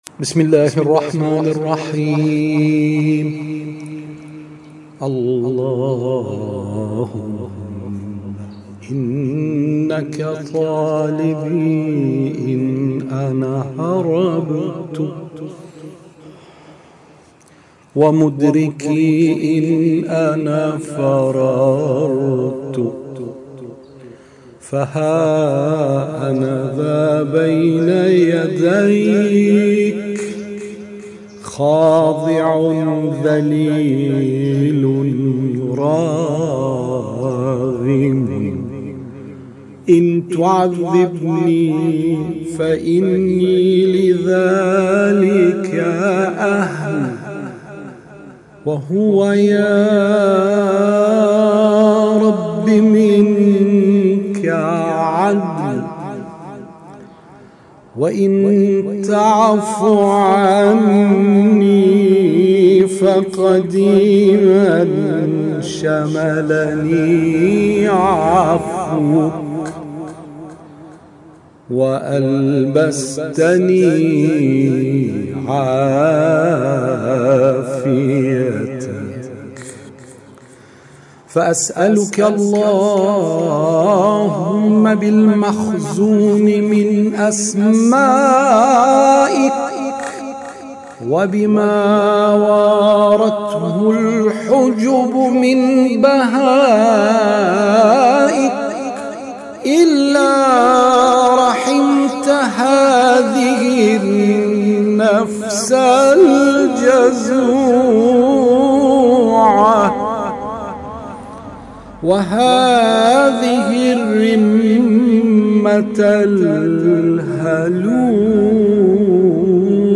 پیشکسوت قرآنی کشورمان فراز پنجاهم صحیفه سجادیه را که به موضوع ترس از خدای متعال مربوط است، قرائت کرد.